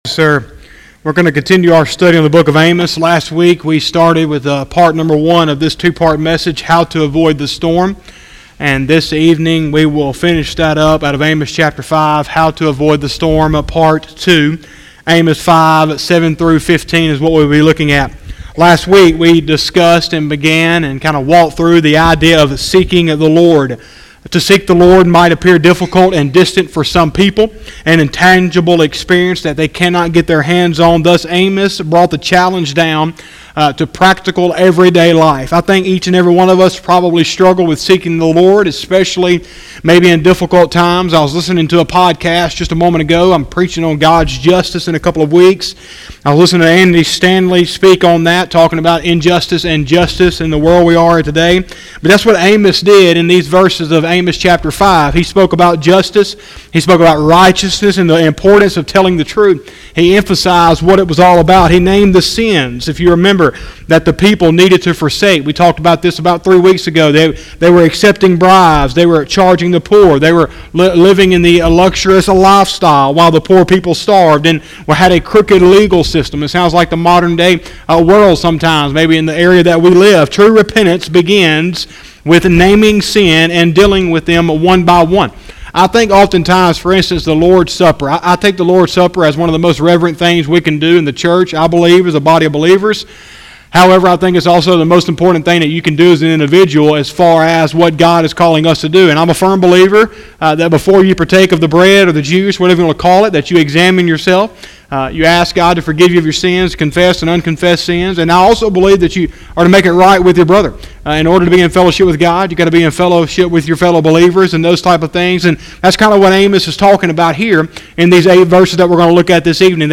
03/04/2020 – Wednesday Evening Service